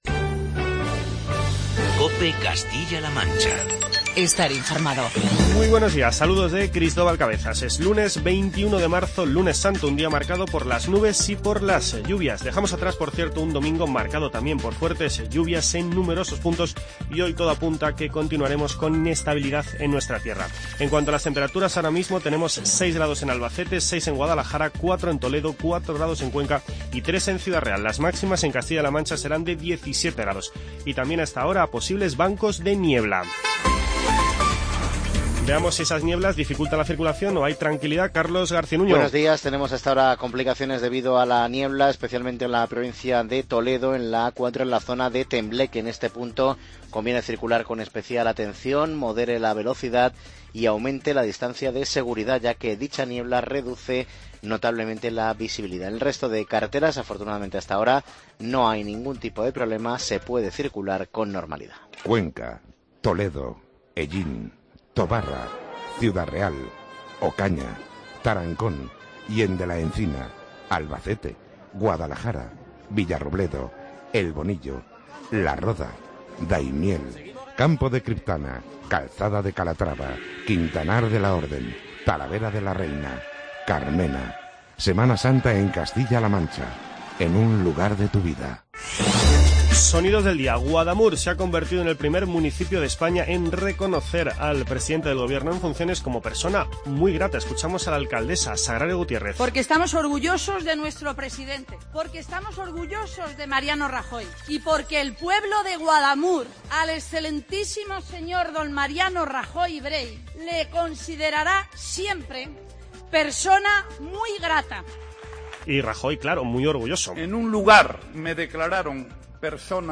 Informativo regional